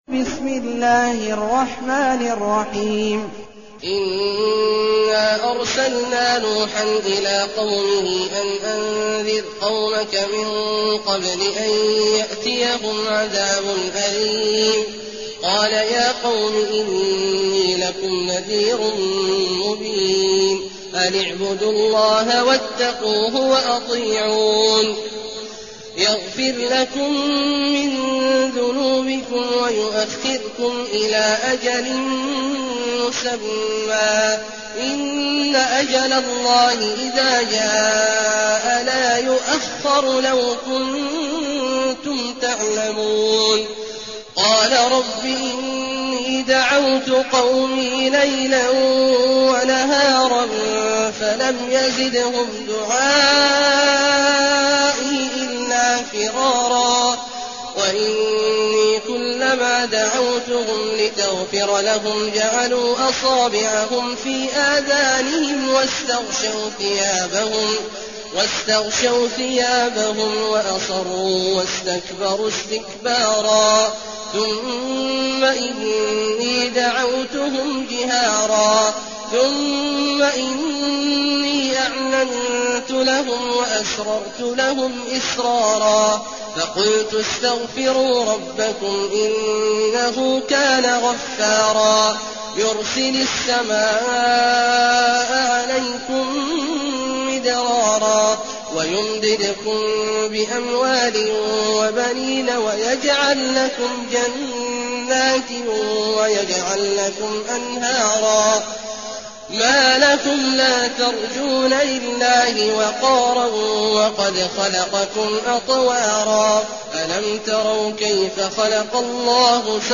المكان: المسجد النبوي الشيخ: فضيلة الشيخ عبدالله الجهني فضيلة الشيخ عبدالله الجهني نوح